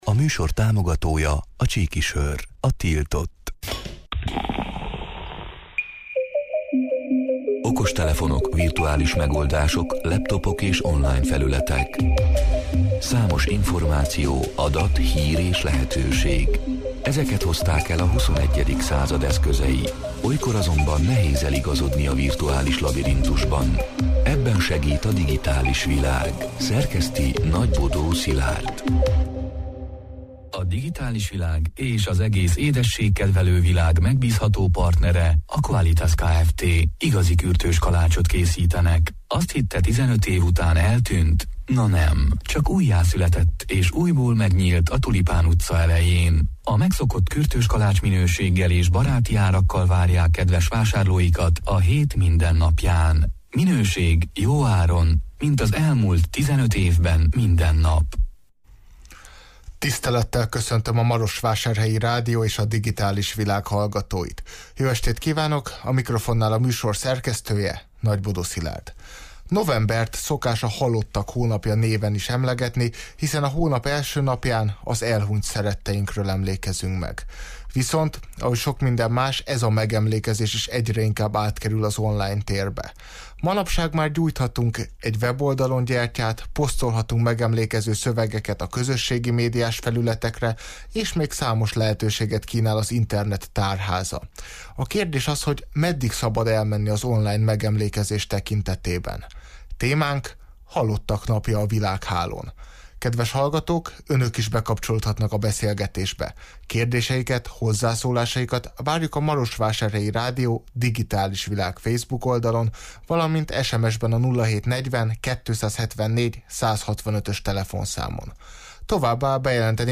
A Marosvásárhelyi Rádió Digitális Világ (elhangzott: 2022. november 1-jén, kedden este 8 órától élőben) c. műsorának hanganyaga: Novembert szokás a halottak hónapja néven is emlegetni, hiszen a hónap első napján az elhunyt szeretteinkről emlékezünk meg.